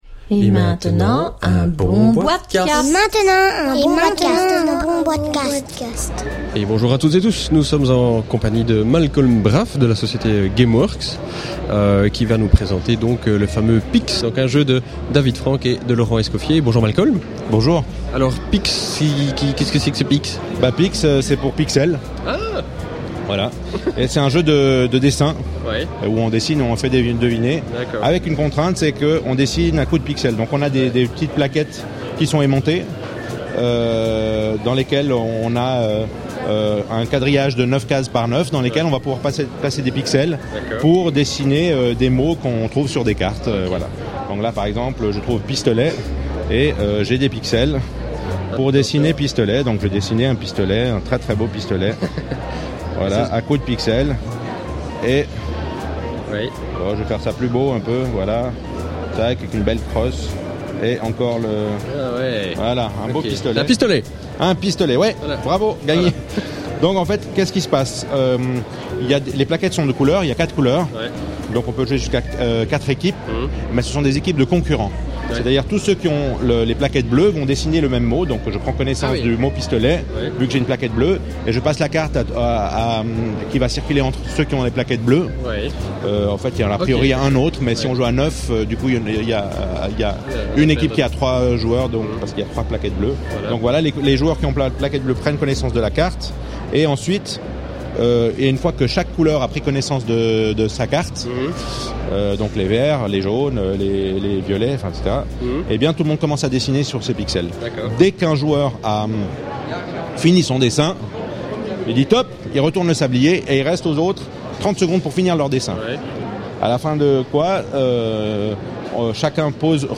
(enregistré lors du salon international de la Nuremberg Toy Fair 2010)